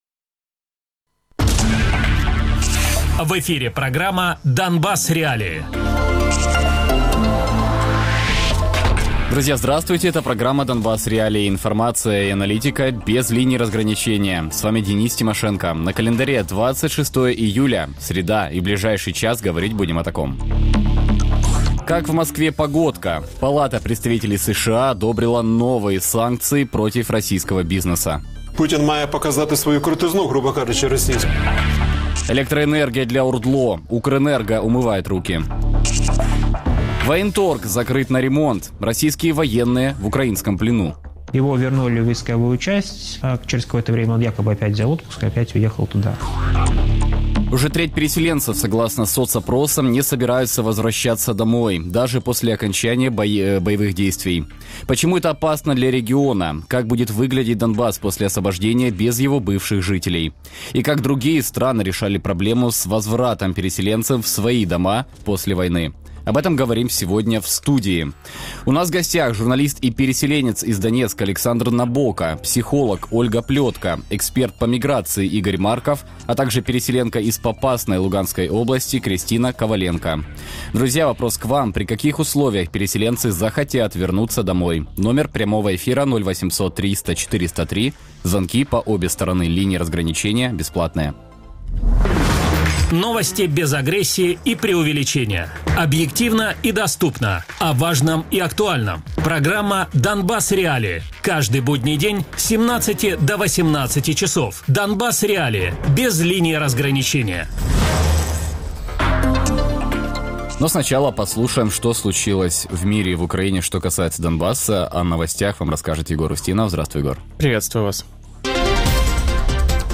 Радіопрограма «Донбас.Реалії»